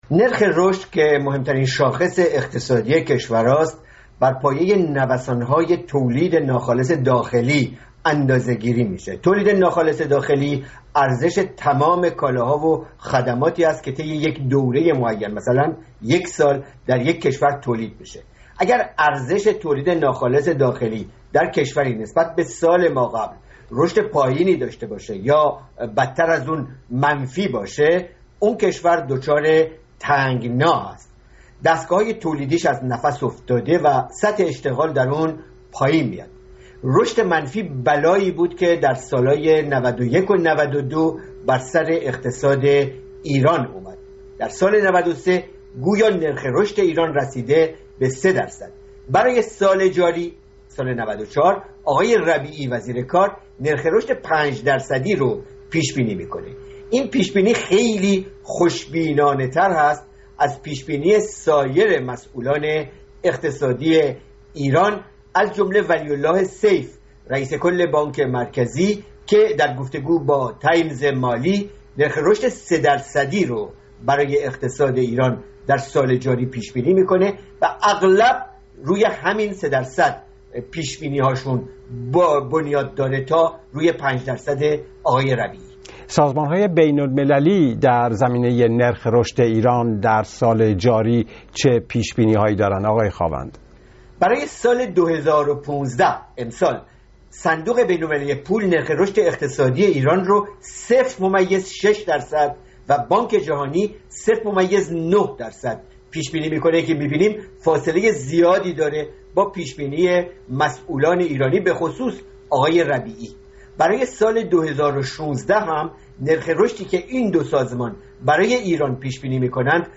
پیش‌بینی نرخ رشد اقتصادی ایران؛ گفت‌وگوی